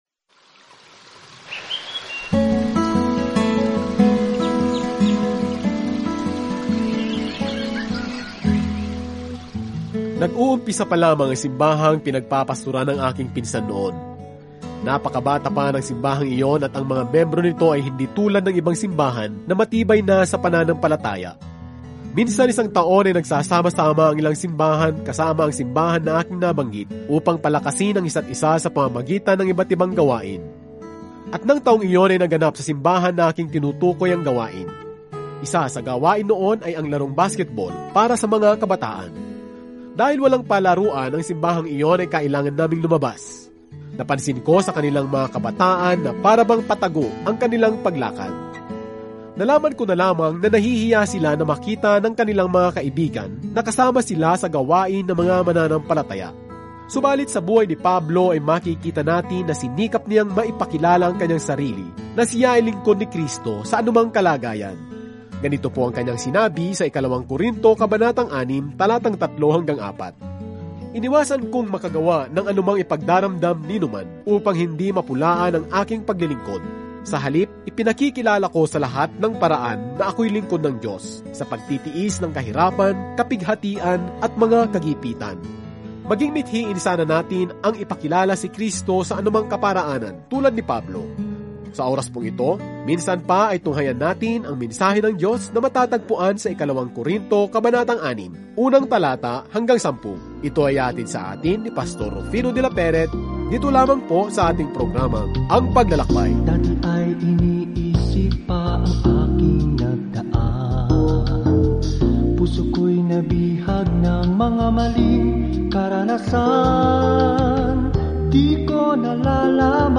Araw-araw na paglalakbay sa 2 Corinthians habang nakikinig ka sa audio study at nagbabasa ng mga piling talata mula sa salita ng Diyos.